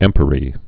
(ĕmpə-rē)